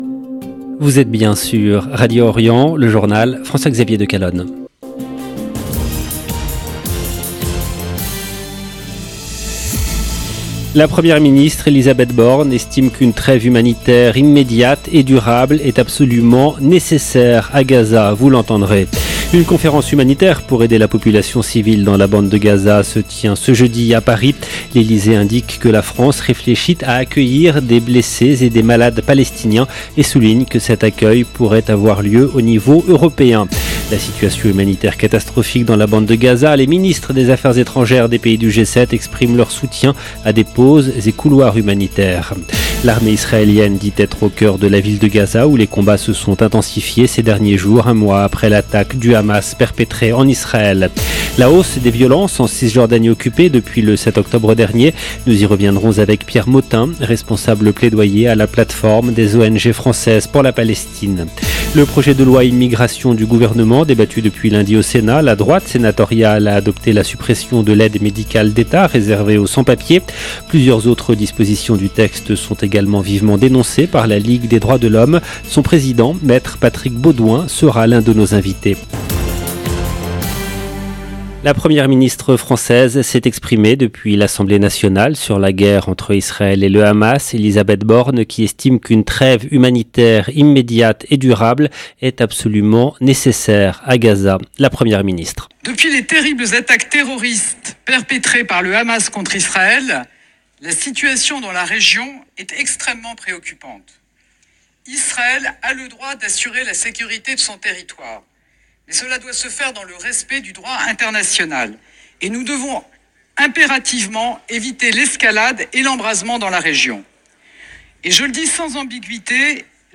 LE JOURNAL EN LANGUE FRANÇAISE DU SOIR DU 8/11/23